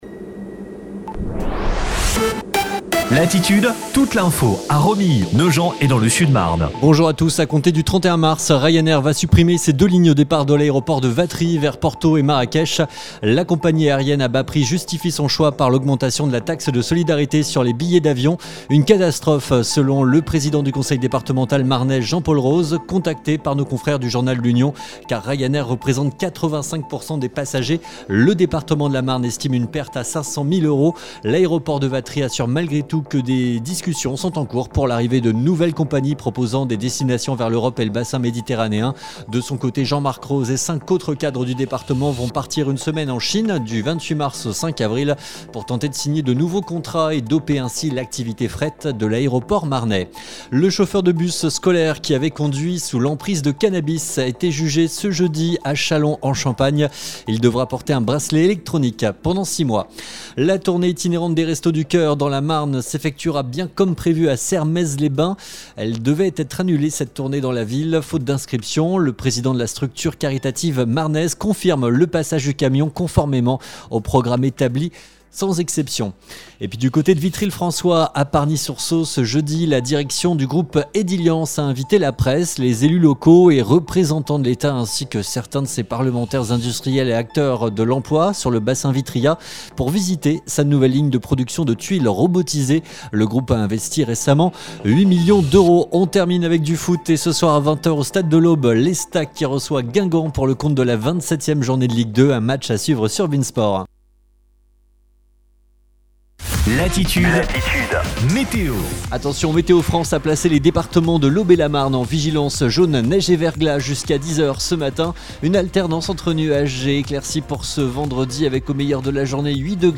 REPLAY – DERNIER FLASH INFO